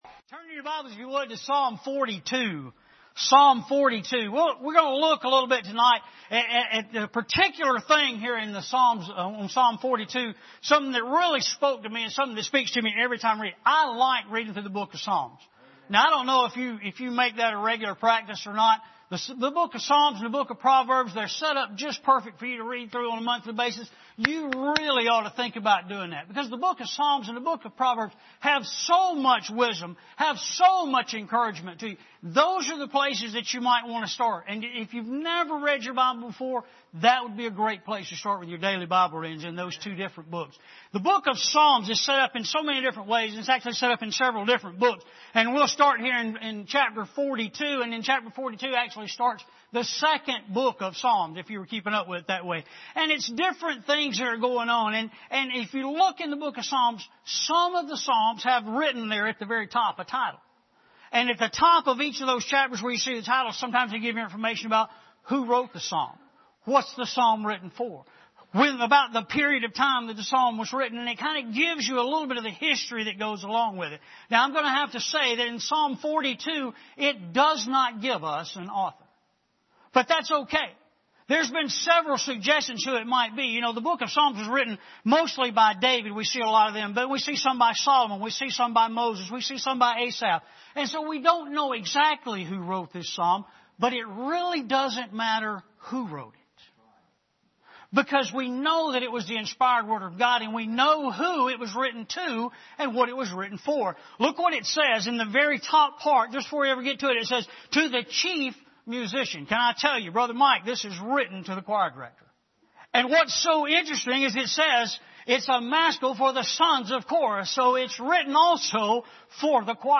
Passage: Psalm 42:1-11 Service Type: Sunday Evening